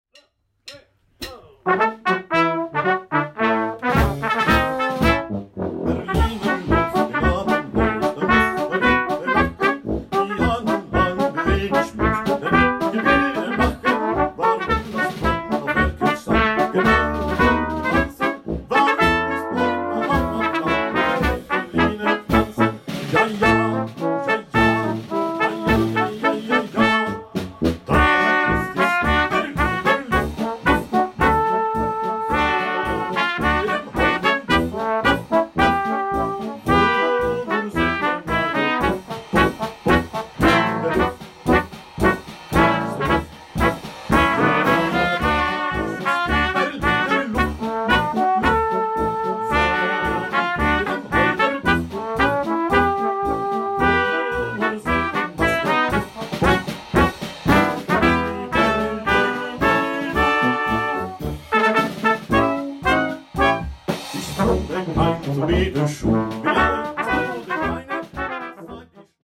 • Balkan/Ompa-ompa
SÅNG